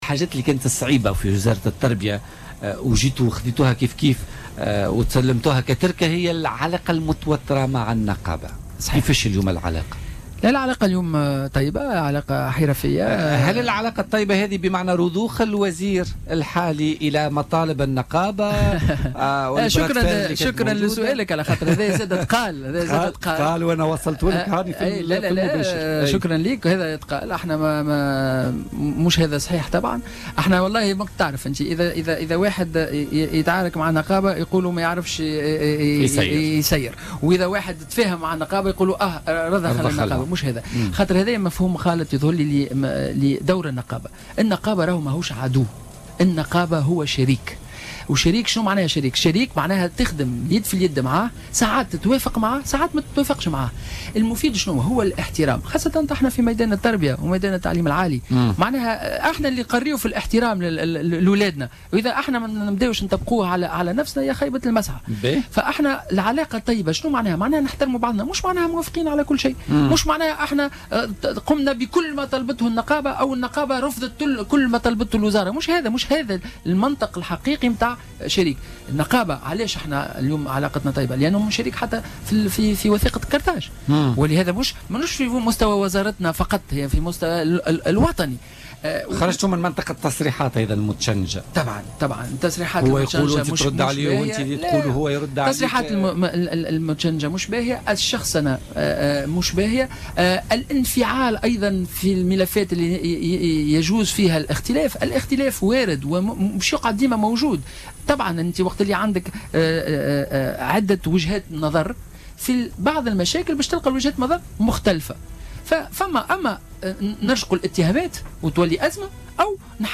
وأضاف خلبوس ضيف برنامج "بوليتيكا" اليوم أنه لابد من الابتعاد عن التصريحات المتشنجة وخاصة الانفعال في الملفات التي فيها اختلاف، وفق تعبيره.